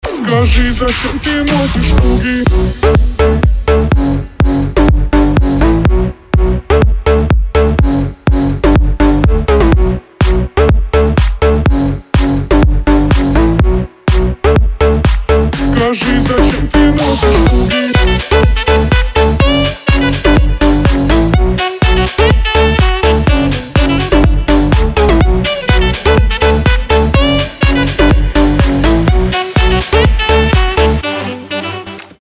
dance
house